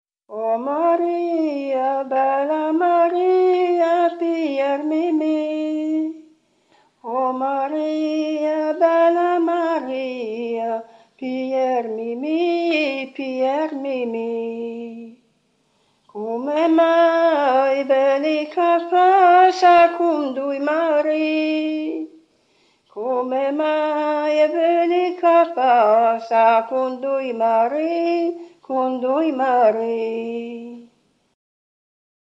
Donna lombarda / [registrata a Casale Popolo, Casale Monferrato (AL), nel 1982